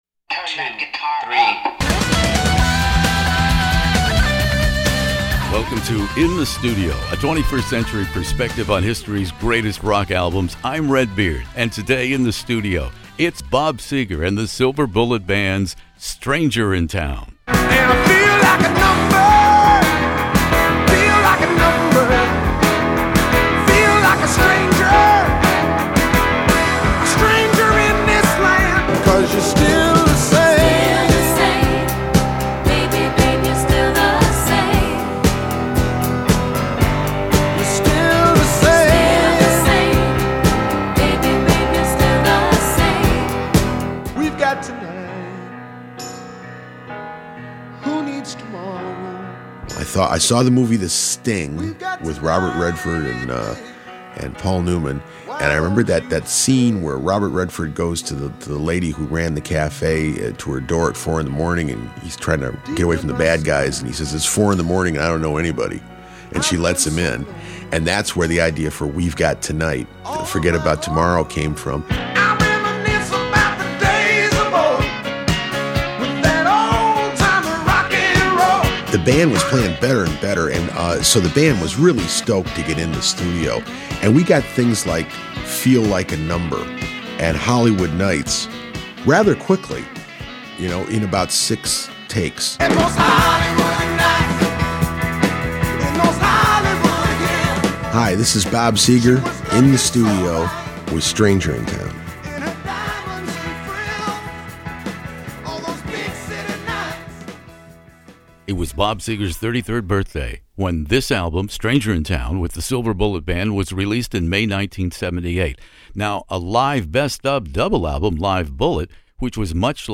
Bob Seger “Stranger in Town” interview